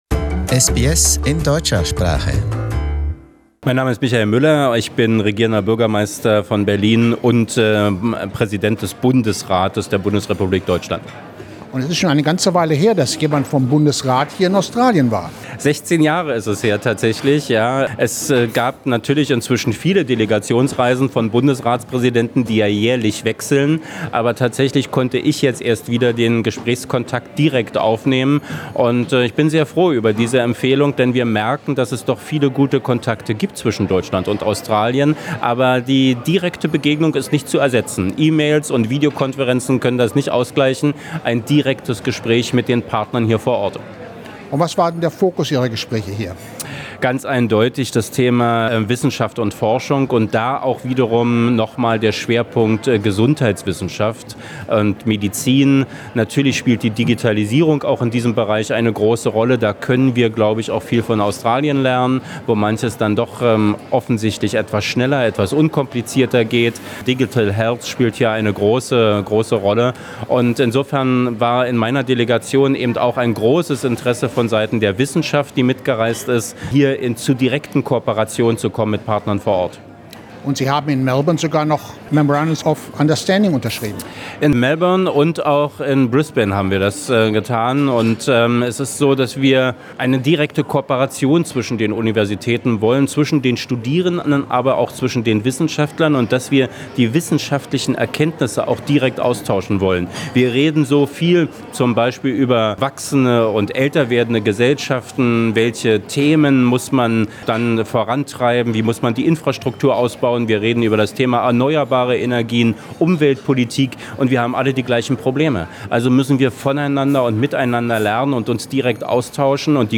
Interview with Berlin Mayor